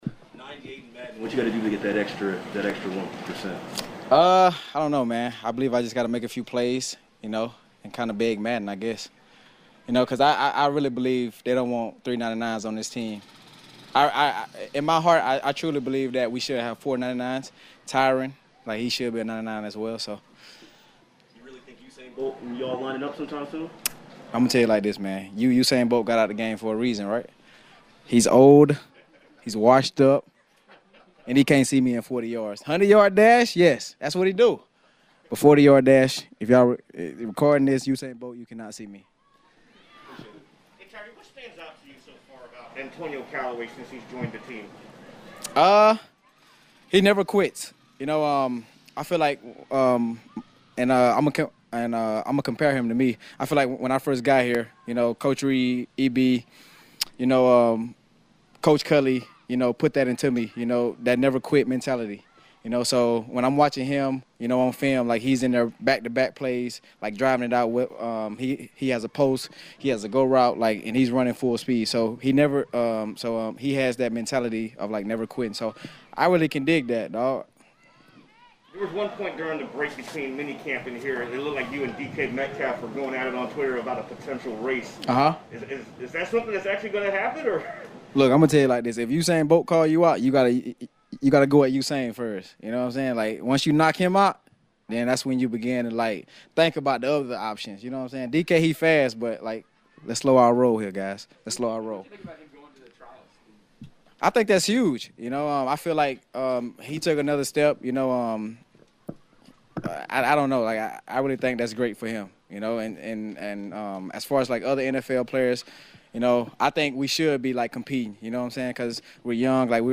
Tyreek Hill visits with the media after Fridays practice.